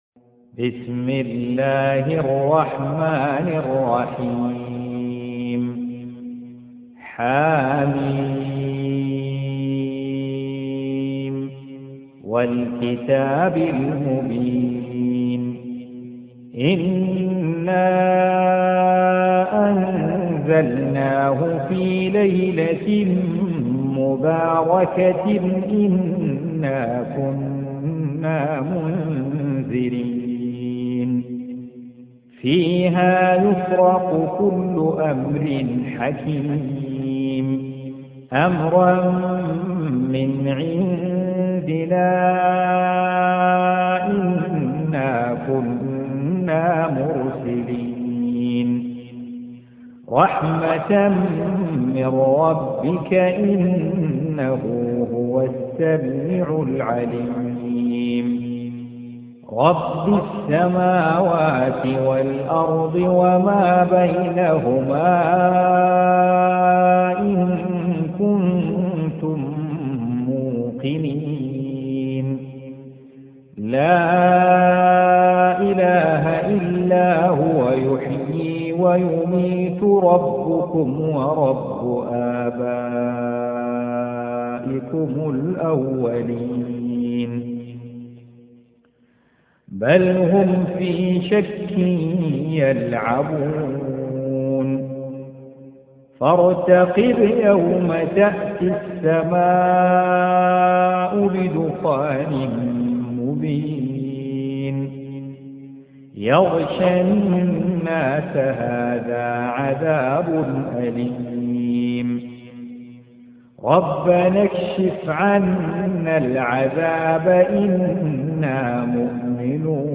Audio Quran Tarteel Recitation
Surah Repeating تكرار السورة Download Surah حمّل السورة Reciting Murattalah Audio for 44. Surah Ad-Dukh�n سورة الدّخان N.B *Surah Includes Al-Basmalah Reciters Sequents تتابع التلاوات Reciters Repeats تكرار التلاوات